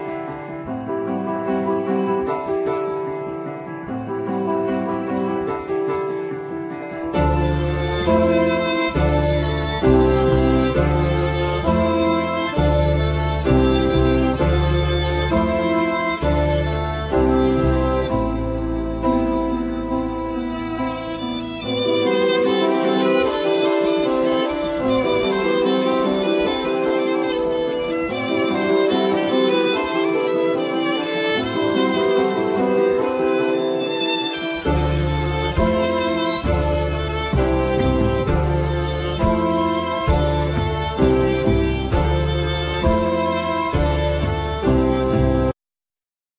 Piano,Keyboards,Vocals
Double bass
Violin,Viola
Bassoon
Cello